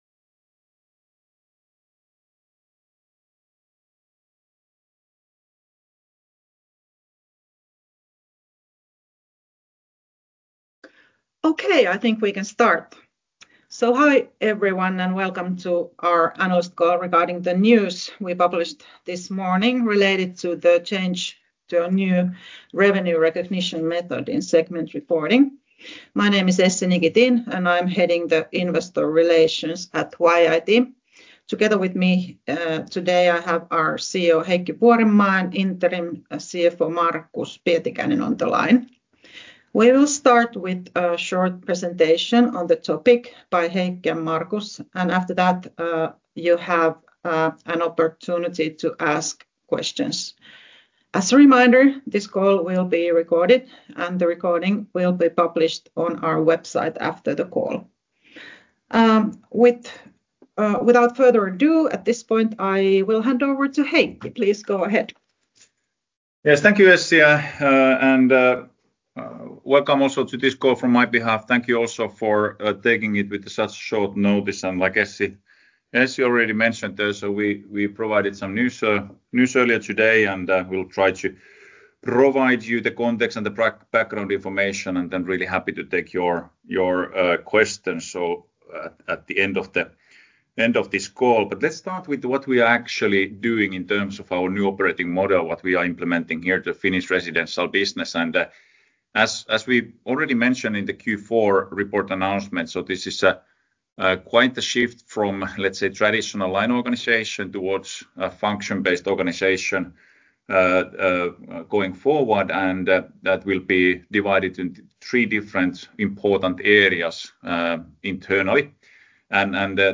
yit-analyst-call-regarding-the-adoption-of-percentage-of-completion-revenue-recognition-method-in-segment-reporting.mp3